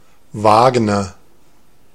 Ääntäminen
Ääntäminen Tuntematon aksentti: IPA: [ˈvaːɡnɐ] Haettu sana löytyi näillä lähdekielillä: saksa Käännös 1.